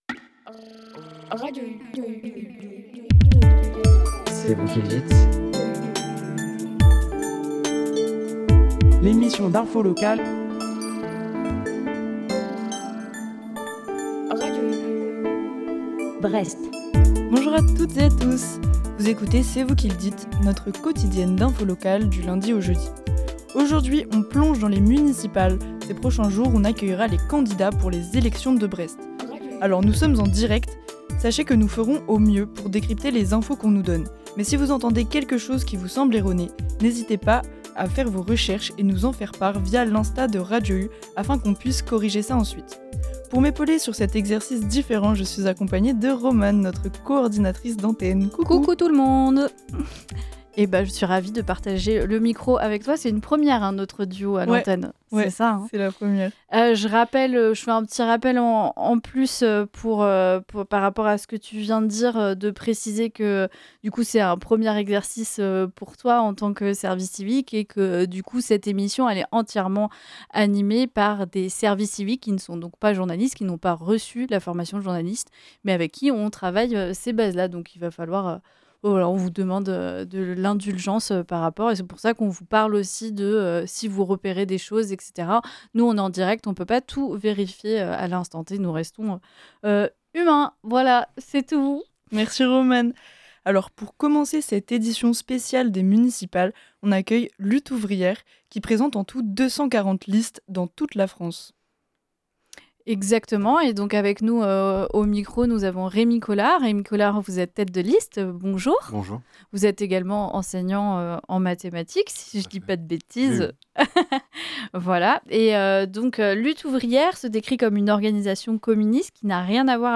Lors de cette semaine, on partage l'antenne avec les candidats des élections municipales de Brest 2026.